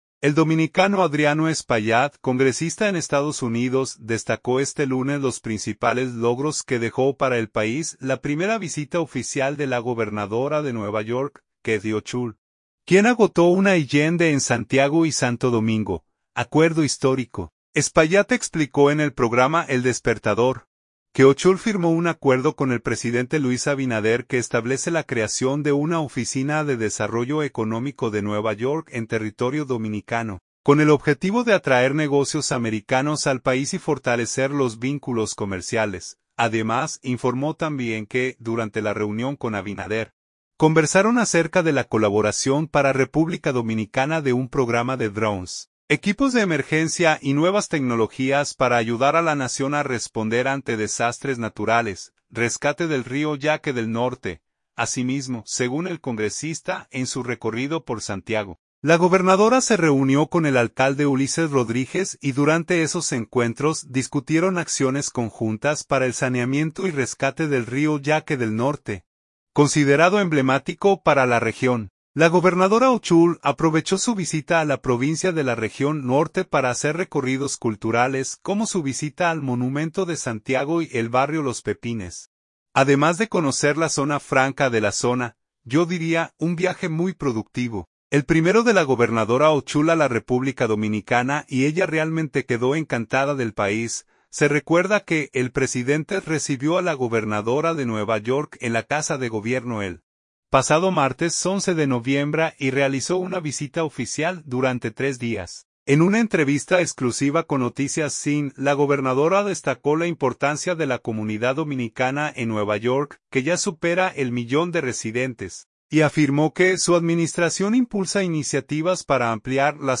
Espaillat explicó en el programa El Despertador, que Hochul firmó un acuerdo con el presidente Luis Abinader que establece la creación de una oficina de desarrollo económico de Nueva York en territorio dominicano, con el objetivo de atraer negocios americanos al país y fortalecer los vínculos comerciales.